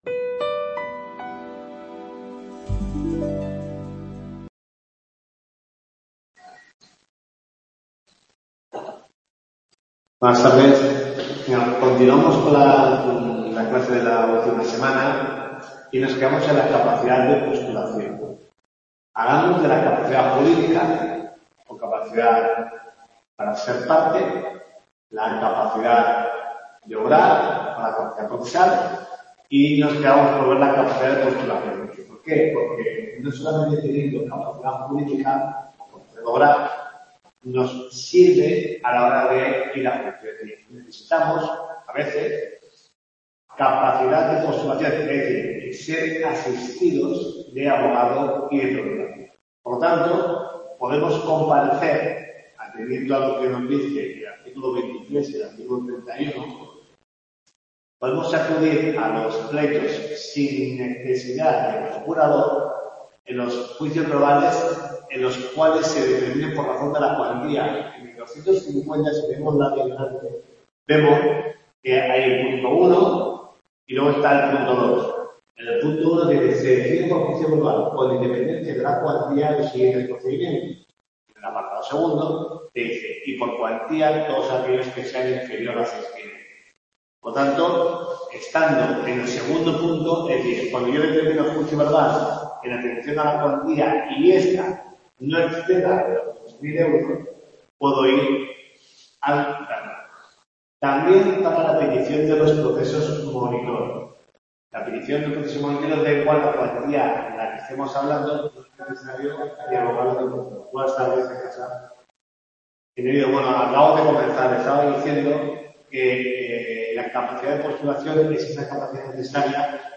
TUTORIA 4